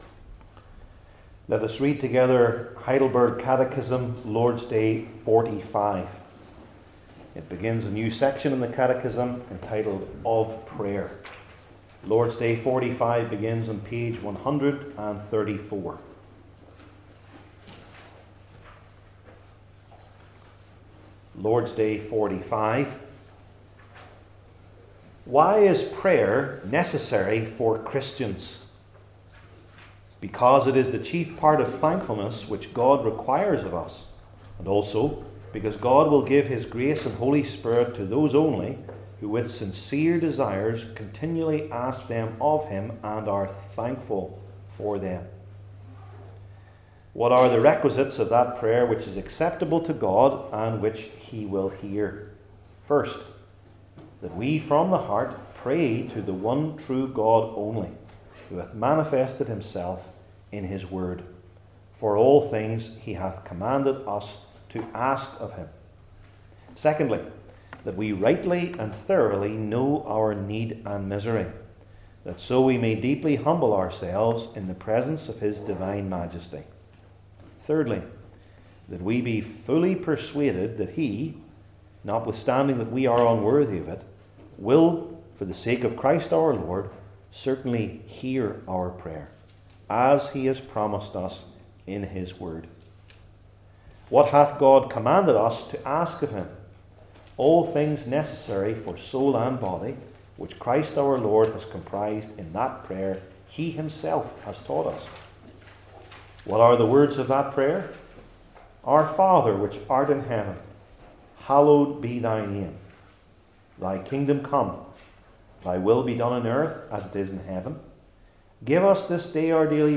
Heidelberg Catechism Sermons I. Before Praying II.